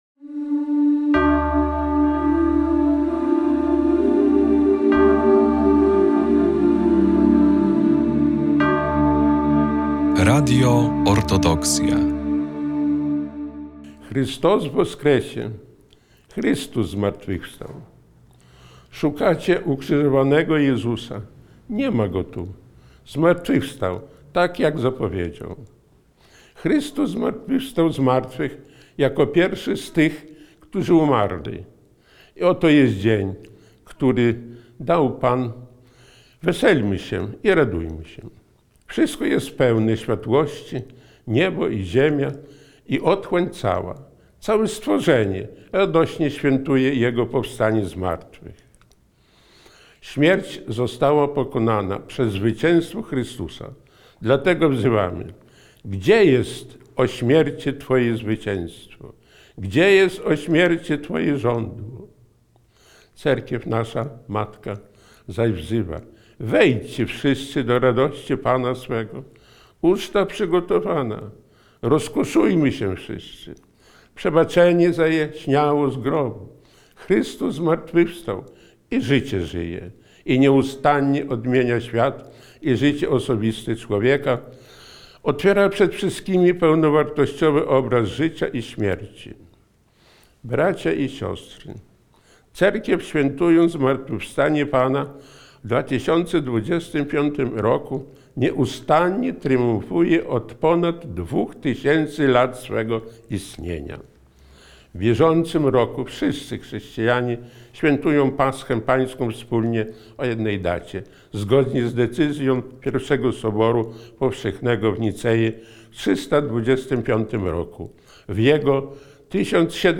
Paschalne orędzie Jego Eminencji Metropolity Sawy 2025
Zapraszamy do wysłuchania paschalnego orędzia Jego Eminencji Najprzewielebniejszego Sawy Metropolity Warszawskiego i całej Polski.